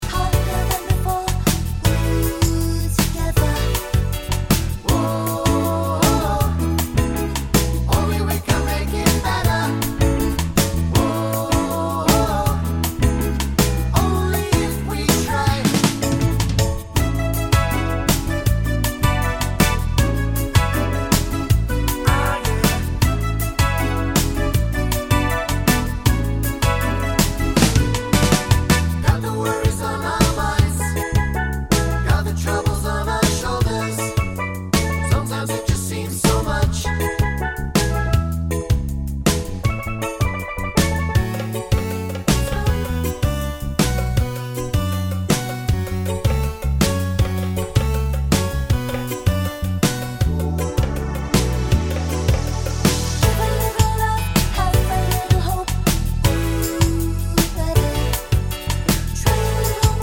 no Backing Vocals Reggae 3:24 Buy £1.50